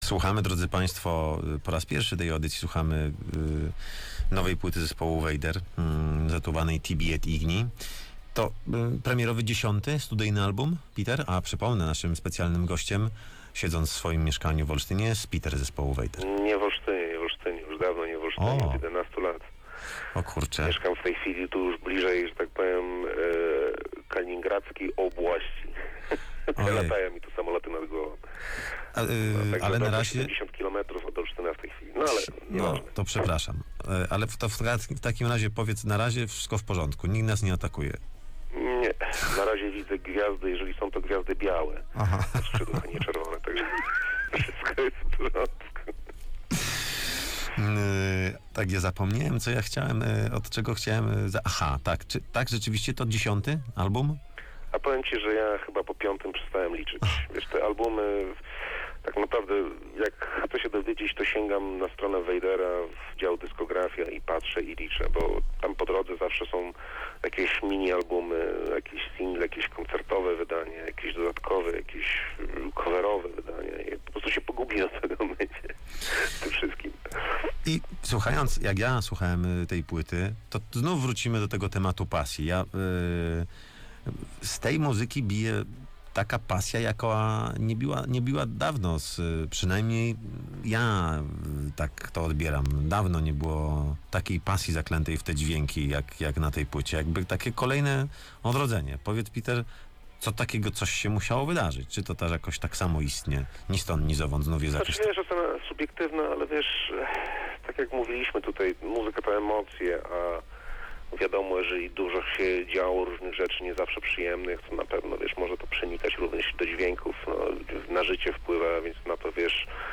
Rozmowa z Peterem z zespołu Vader - Radio Łódź